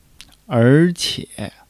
er2--qie3.mp3